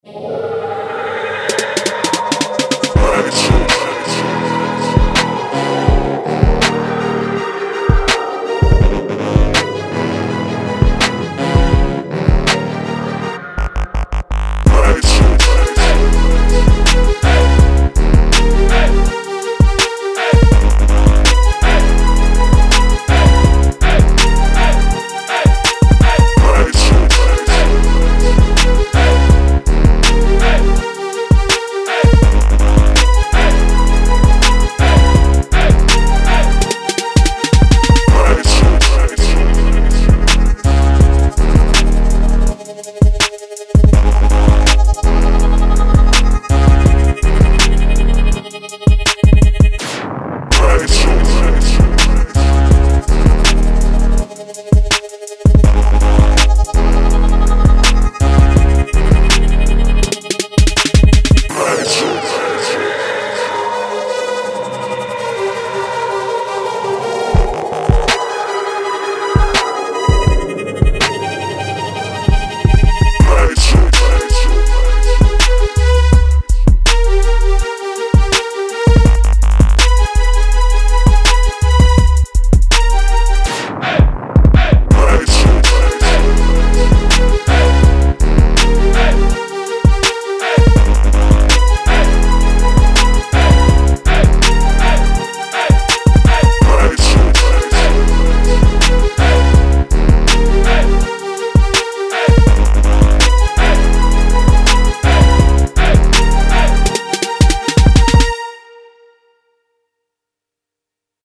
Скачать Минус
Стиль: Rap